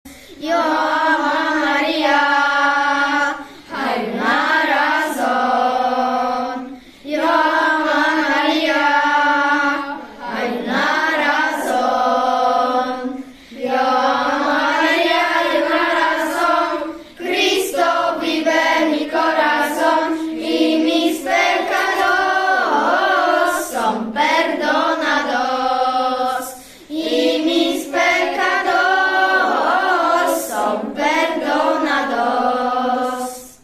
Wcześniej każda klasa przygotowała plakat o jednym z krajów z kontynentu, który jej został wyznaczony, a niektóre klasy nauczyły się śpiewać piosenki w języku w jakich mówi się na danym kontynencie czy też w kraju, o którym przygotowywały plakat.